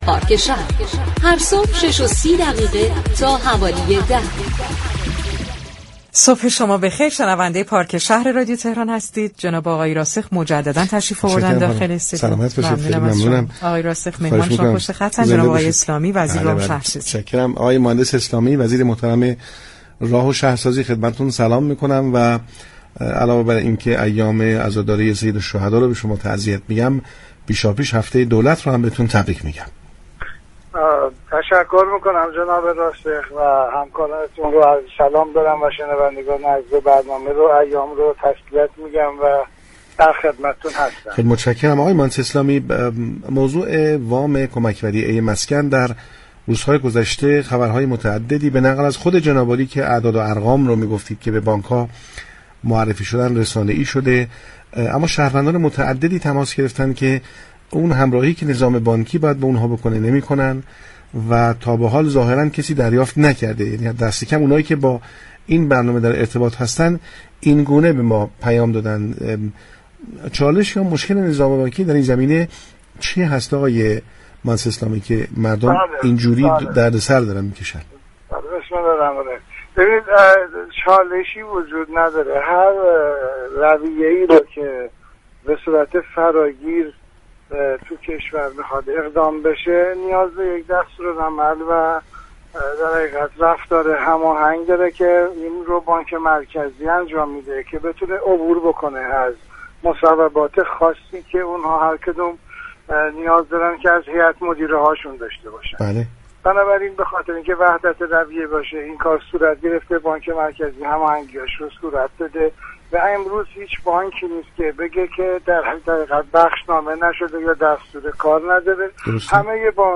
محمداسلامی، وزیر راه و ‌شهرسازی در گفتگو با پارك شهر رادیو تهران اقدامات و طرح های وزارت مسكن برای كمك به خانه دارشدن نیازمندان و جلوگیری از رشد نقدینگی و هدایت آن به حوزه مسكن را تشریح كرد.